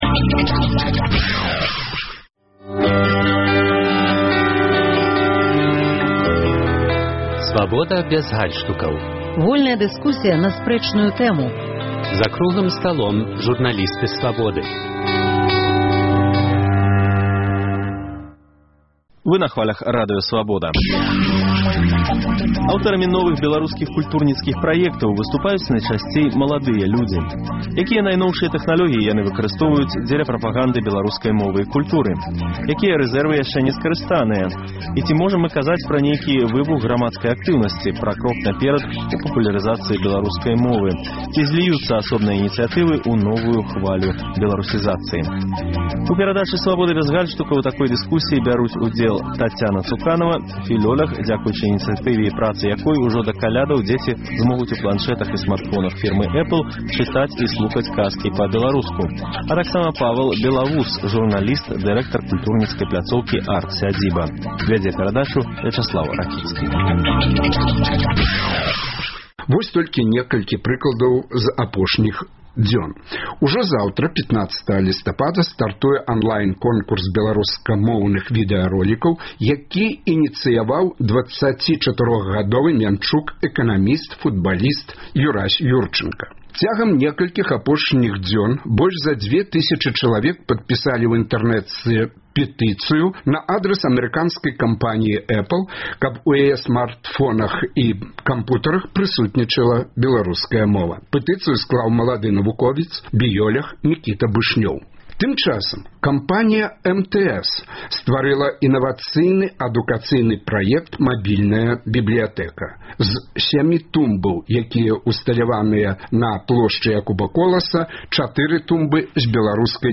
Ці насамрэч мы можам казаць пра нейкі выбух грамадзкай актыўнасьці, пра крок наперад у папулярызацыі беларускай мовы і ці зьліюцца асобныя ініцыятывы ў новую хвалю беларусізацыі? У дыскусіі бяруць удзел